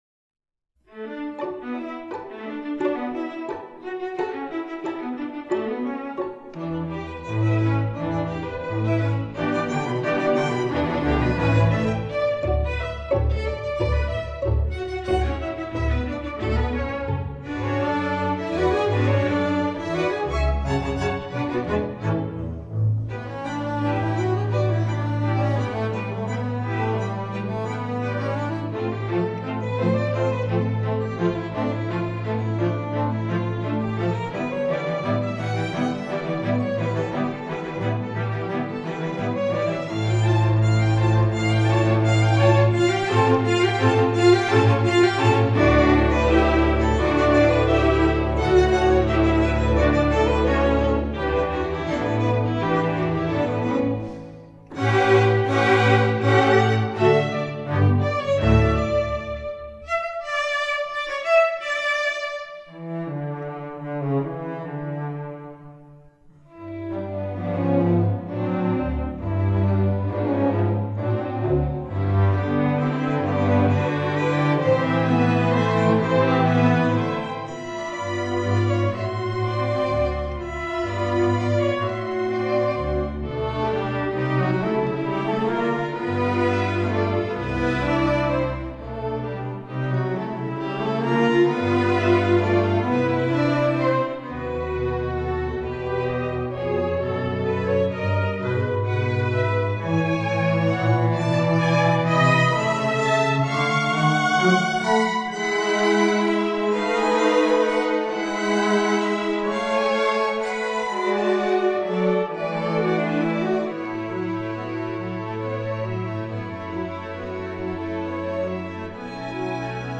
classical, french